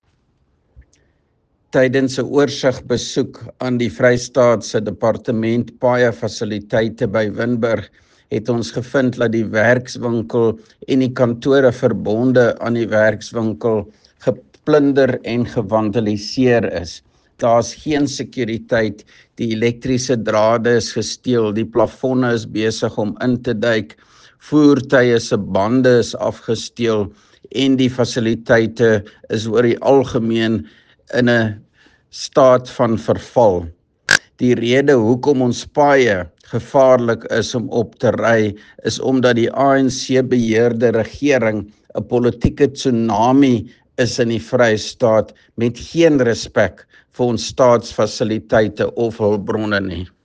Afrikaans soundbites by Roy Jankielsohn MPL and Sesotho soundbite by Jafta Mokoena MPL with images here, here, here, here and here